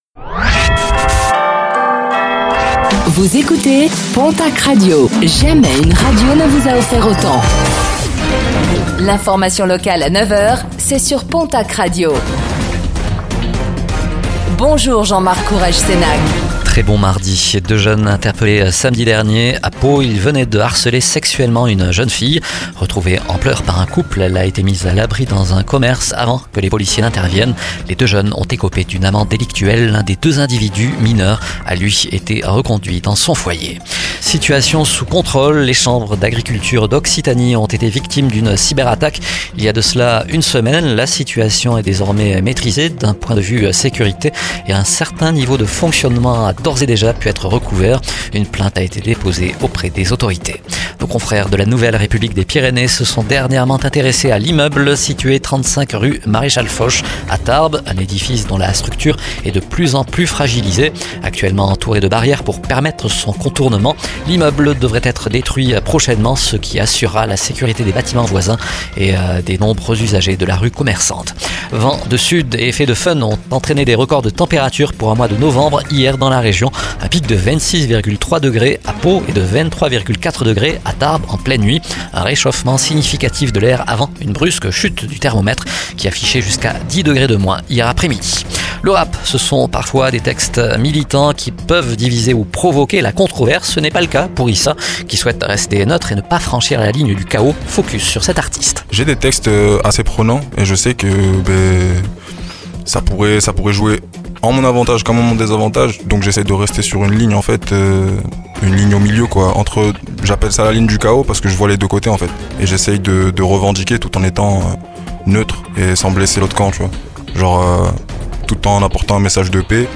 Infos | Mardi 26 novembre 2024